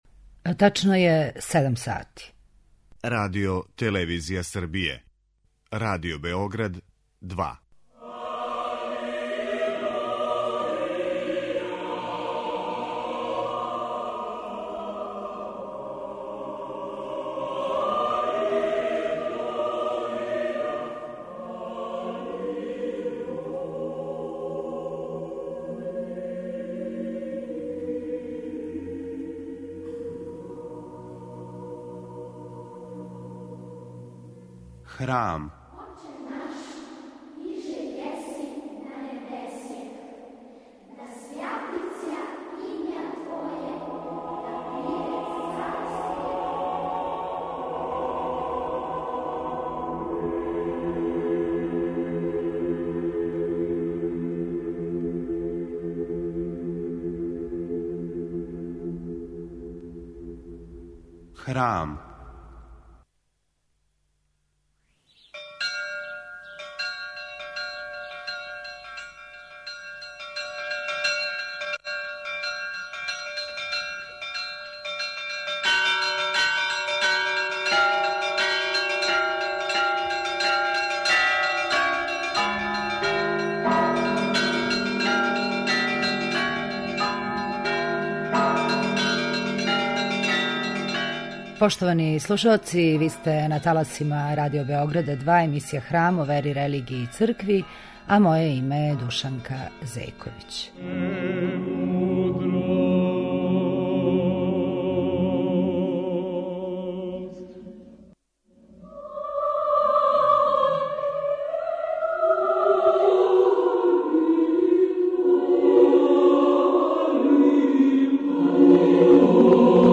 Српска духовна музика